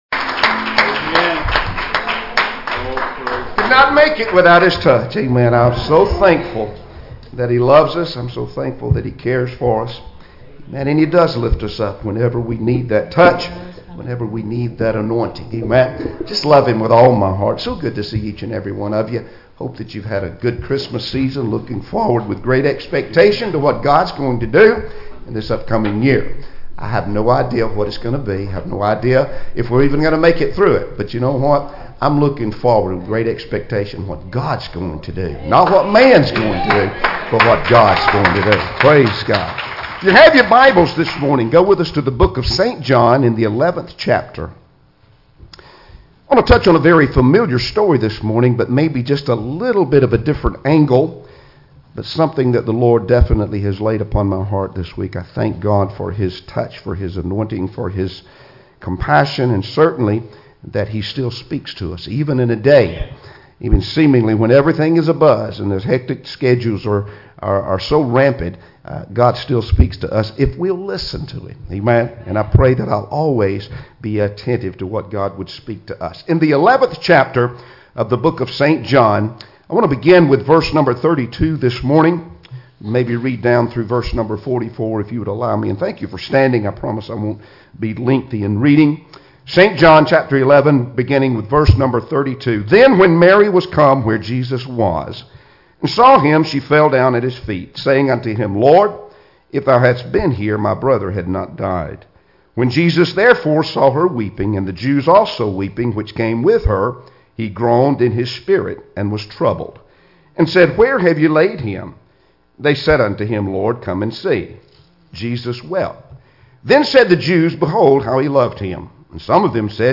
Passage: John 11:32-44 Service Type: Sunday Morning Services Topics